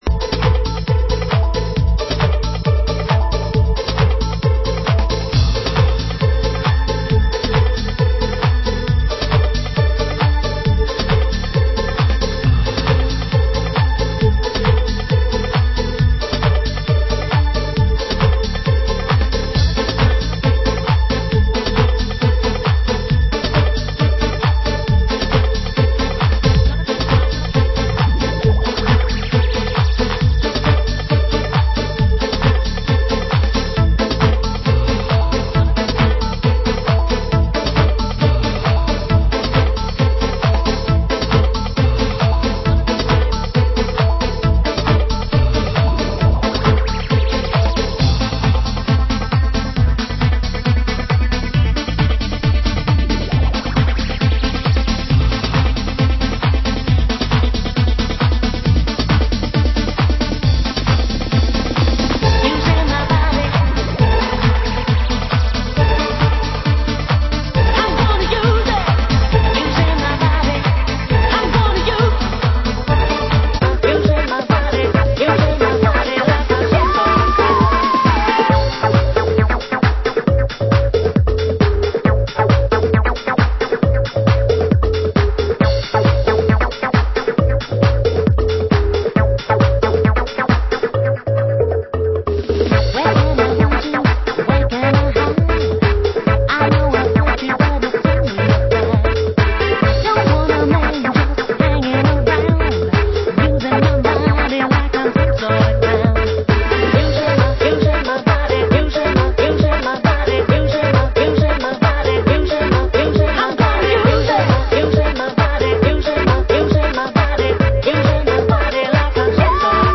Format: Vinyl 12 Inch
Genre: UK Garage